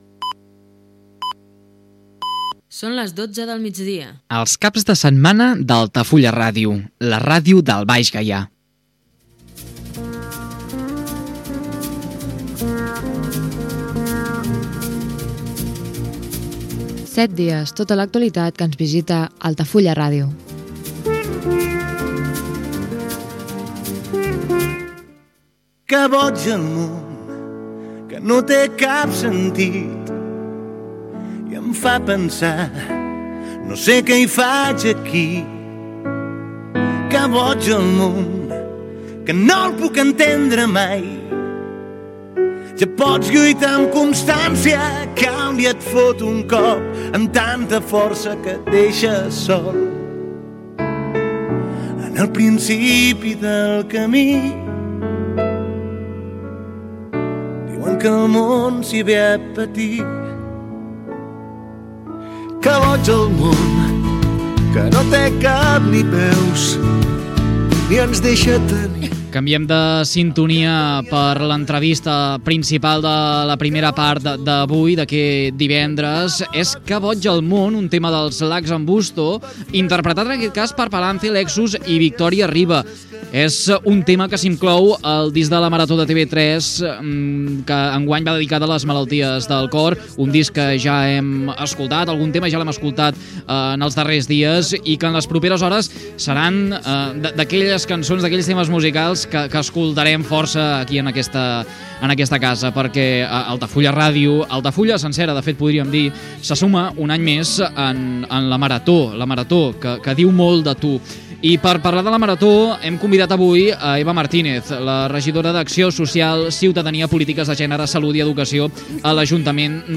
Senyals horaris, indicatiu de l'emissora els caps de setmana. Careta del programa i fragment d'un tema del disc de "La Marató" de TV3 dedicat a les malalties del cor. Fragment d'una entrevista a la regidora d'acció social, ciudadania i educació Eva Martínez.
Info-entreteniment